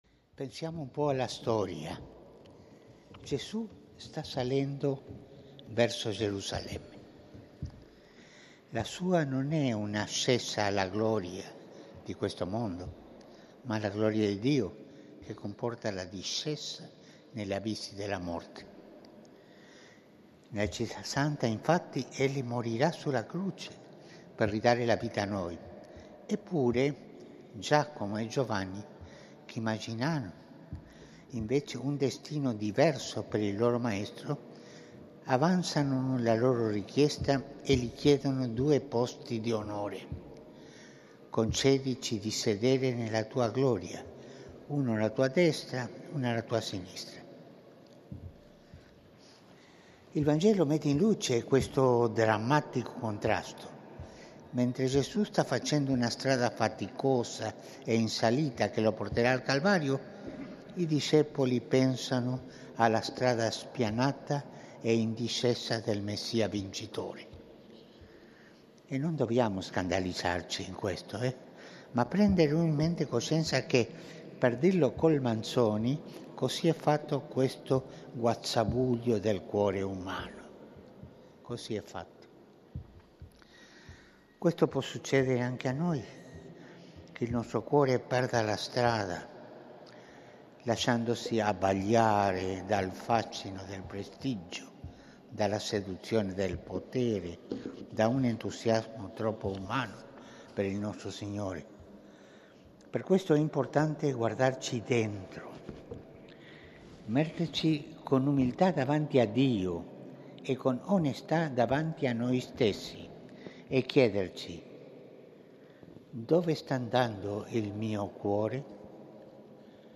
Omelia di Papa Francesco durante il Concistoro Ordinario Pubblico per la Creazione di nuovi Cardinali (19 novembre 2016).